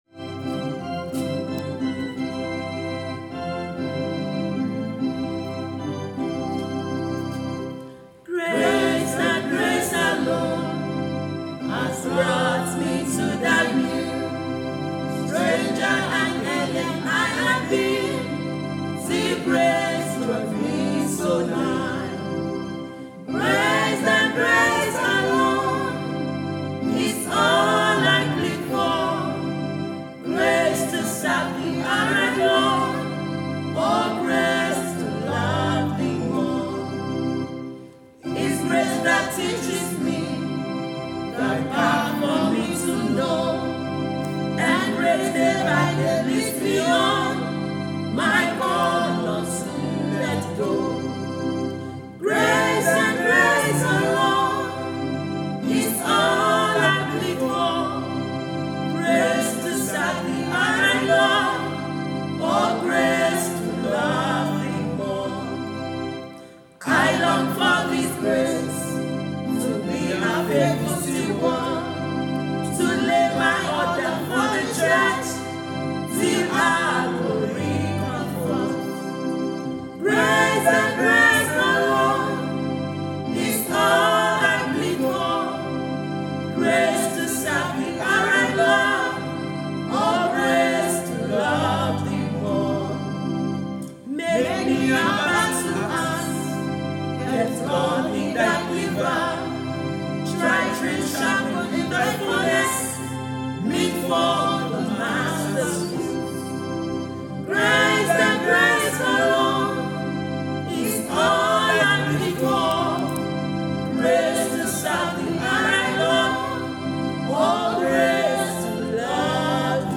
Our School Anthem:
school_anthem.aac